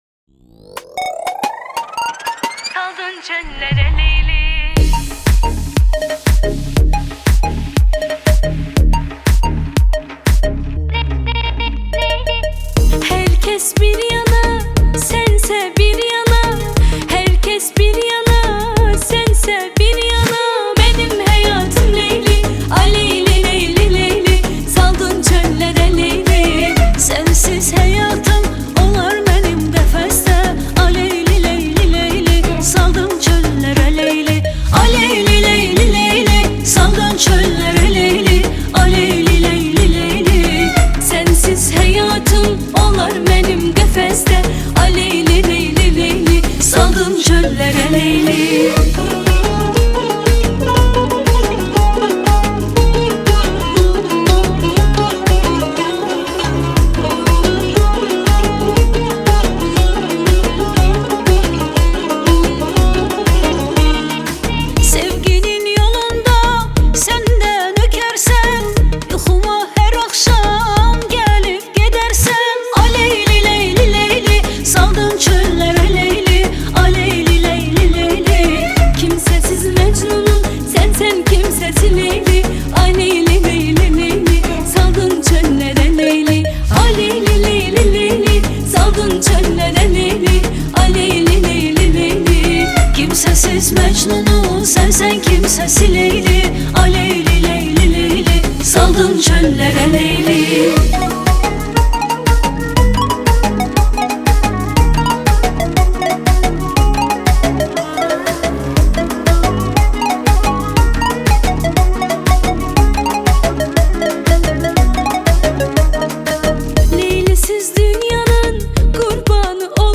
(صدای زن ترکی باکویی)